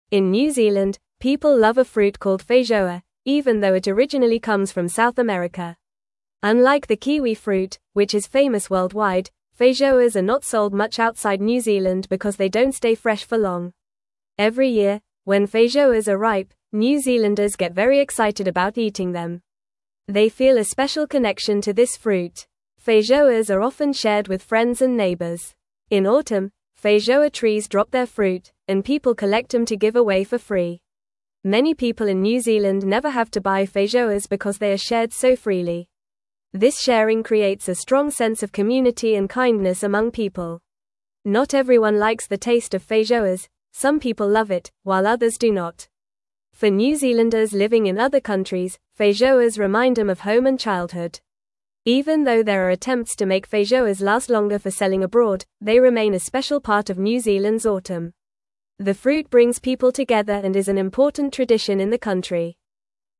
Fast
English-Newsroom-Lower-Intermediate-FAST-Reading-New-Zealands-Special-Fruit-The-Feijoa-Story.mp3